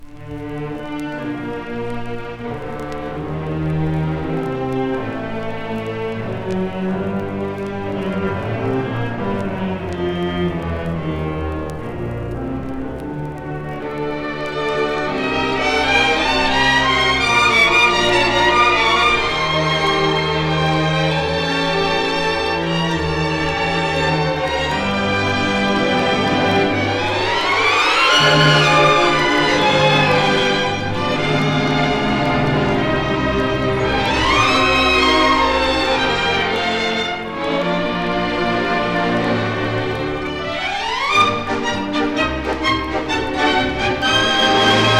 地域色豊かな軽やかさと哀愁、ダンス音楽としても楽しめて興味も湧く軽音楽集です。
Classical, Popular, World　USA　12inchレコード　33rpm　Mono